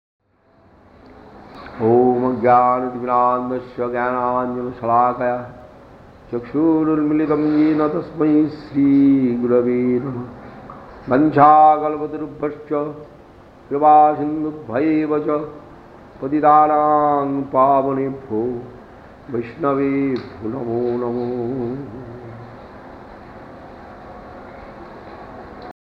Navadwip Dham, India | «Пранам».